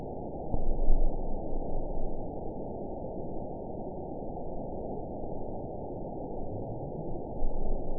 event 912264 date 03/22/22 time 21:14:45 GMT (3 years, 1 month ago) score 8.68 location TSS-AB03 detected by nrw target species NRW annotations +NRW Spectrogram: Frequency (kHz) vs. Time (s) audio not available .wav